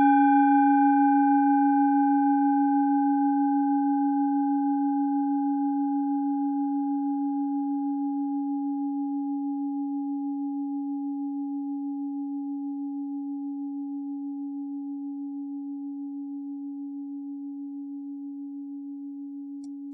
Klangschale Bengalen Nr.20
Sie ist neu und wurde gezielt nach altem 7-Metalle-Rezept in Handarbeit gezogen und gehämmert.
(Ermittelt mit dem Filzklöppel)
klangschale-ladakh-20.wav